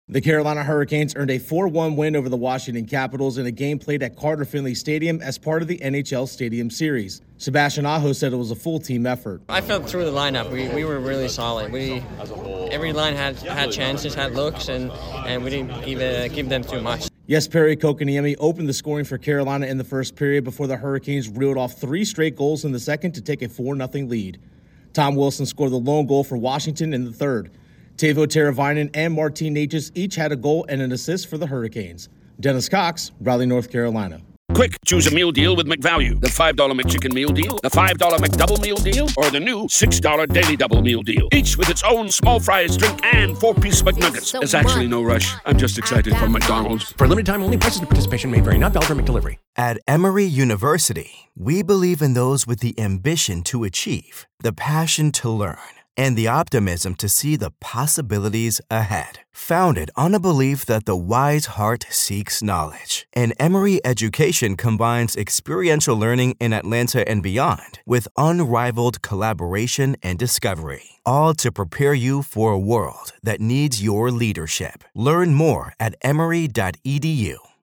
The Hurricanes are winners at the football home of North Carolina State. Correspondent